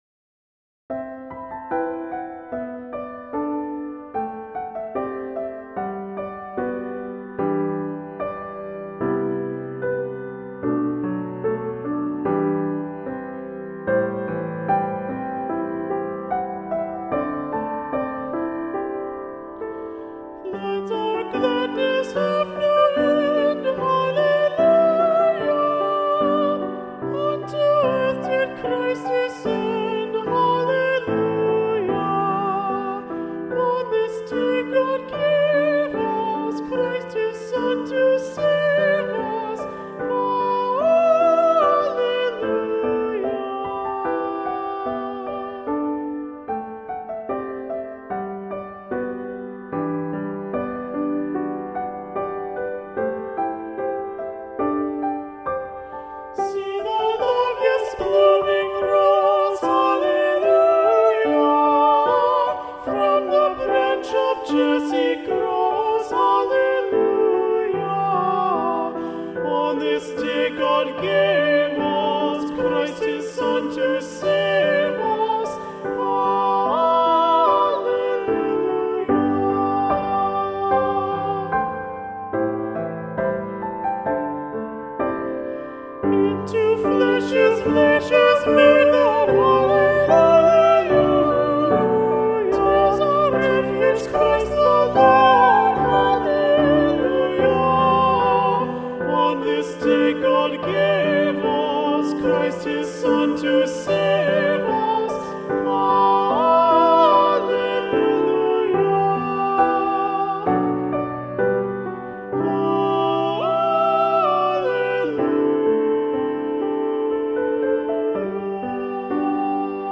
Choral Music
Choral ~ Holiday ~ with Keyboard
(An arrangement of the traditional Bohemian carol)